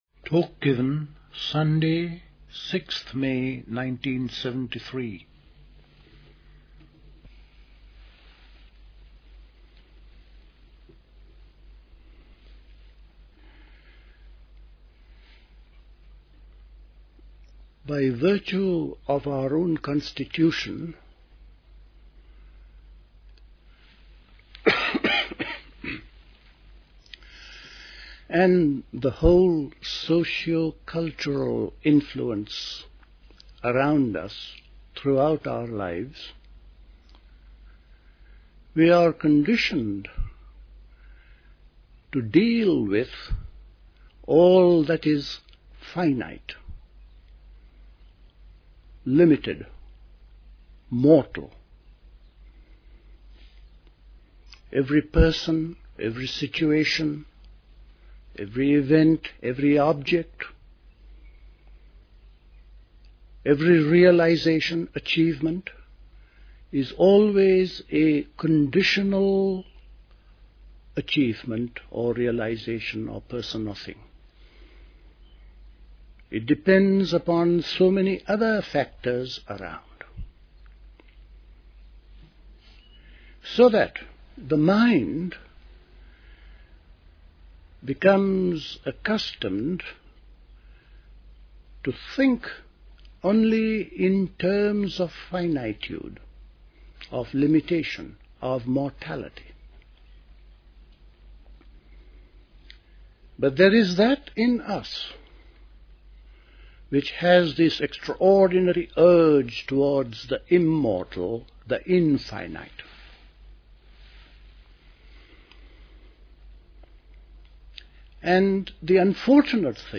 A talk
at Dilkusha, Forest Hill, London on 6th May 1973